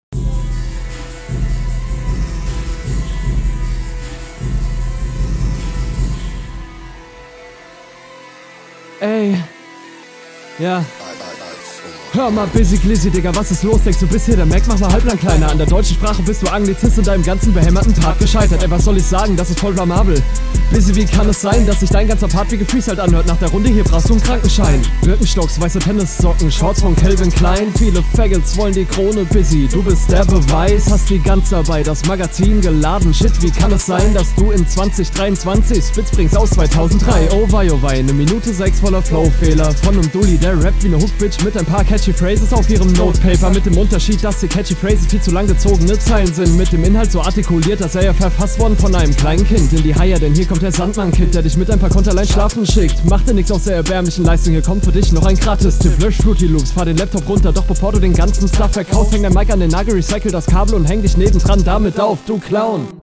Flow deutlich schlechter, nich immer onpoint, Stimmeinsatz recht roh und unroutiniert.
Finde die Betonungen zwischendurch echt sehr whack.